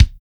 TITE HARD K.wav